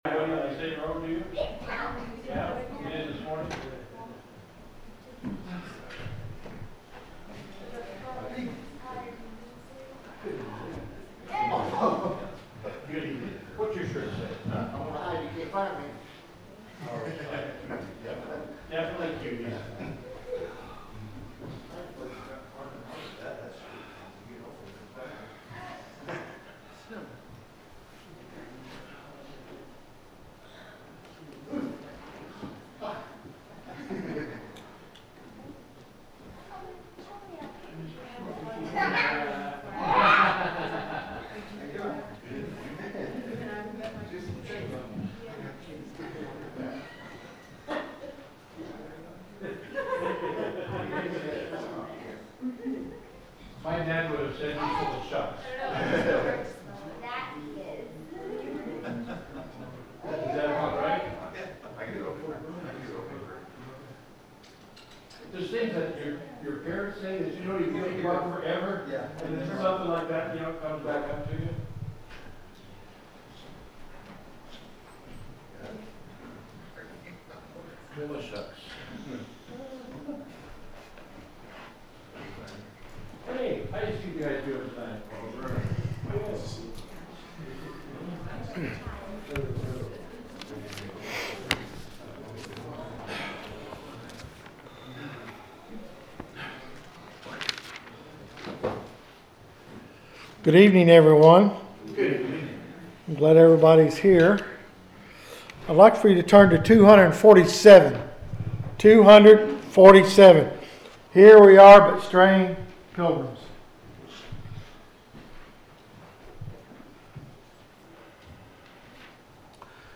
The sermon is from our live stream on 12/21/25